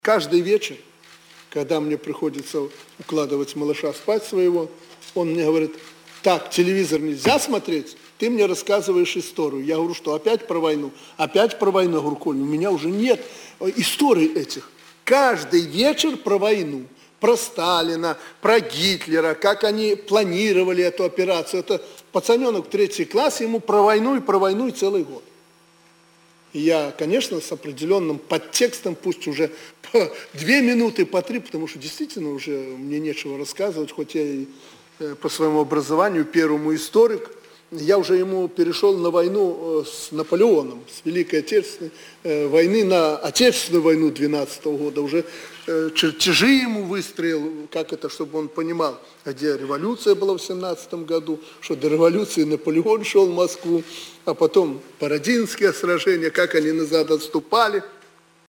Прэсавая канфэрэнцыя Аляксандра Лукашэнкі для расейскіх журналістаў. Менск, 11 кастрычніка 2013